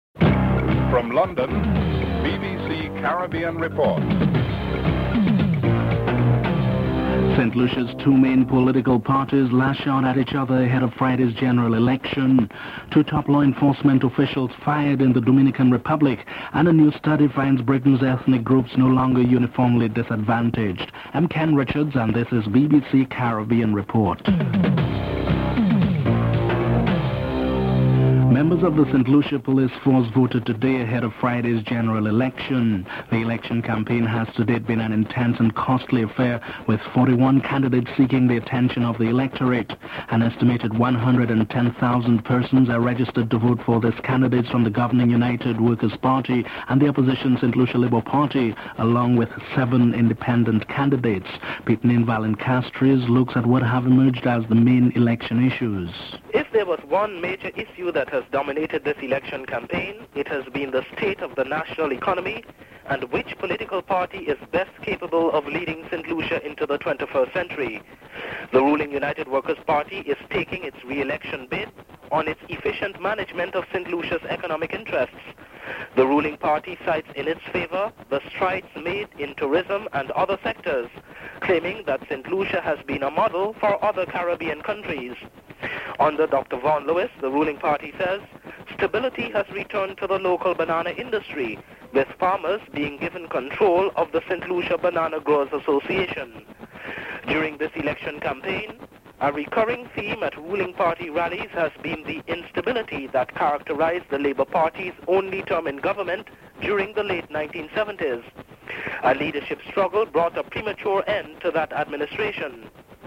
1. Headlines (00:00-00:27)
Prime Minister of Trinidad and Tobago, Basdeo Panday is interviewed.